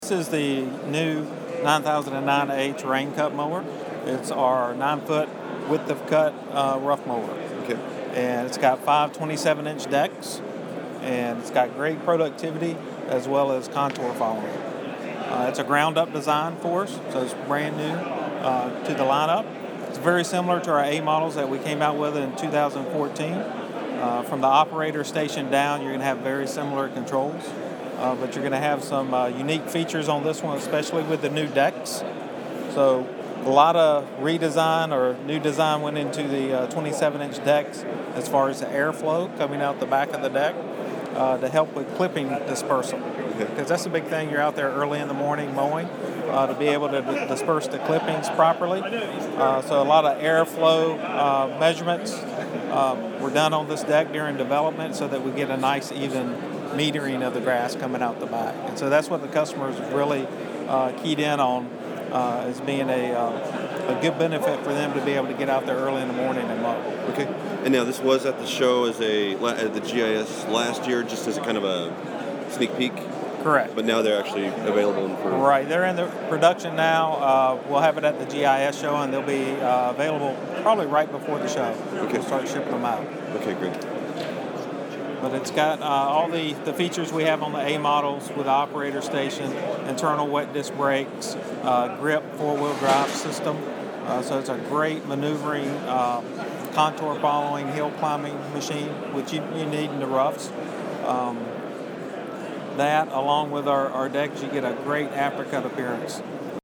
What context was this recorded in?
It was my second trip to the BIGGA Turf Management Exhibition in Harrogate, England, so while I wasn’t as wide-eyed as I was my first time attending the show, I still was impressed to see equipment that hasn’t yet been out in the open in the U.S.